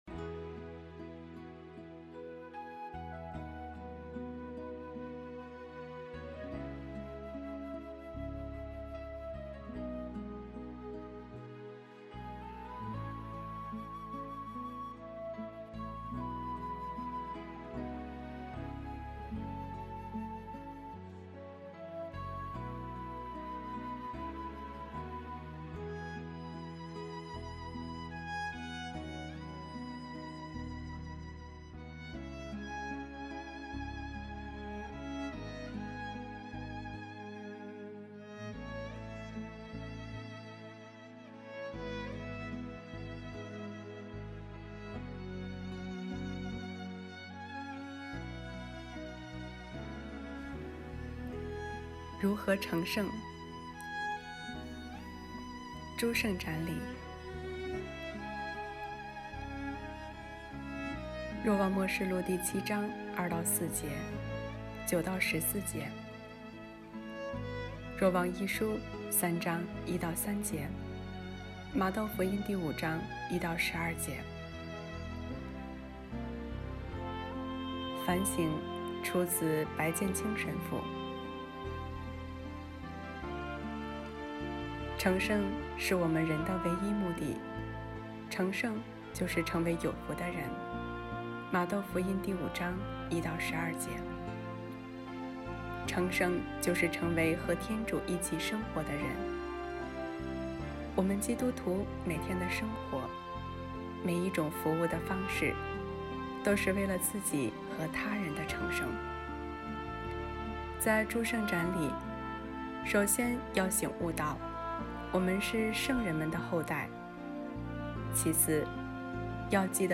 【主日证道】| 如何成圣（诸圣瞻礼）